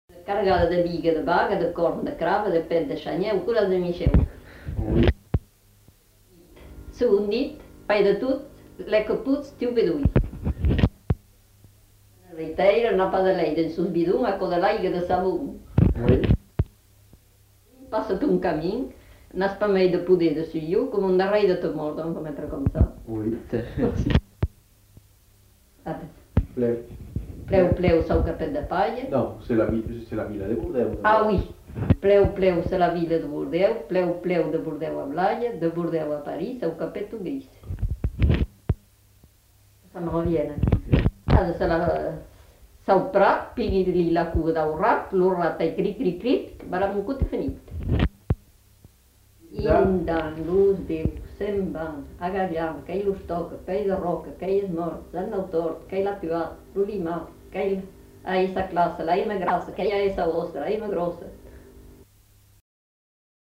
Formulettes enfantines
Aire culturelle : Médoc
Lieu : Bégadan
Genre : forme brève
Effectif : 1
Type de voix : voix de femme
Production du son : récité
Classification : formulette enfantine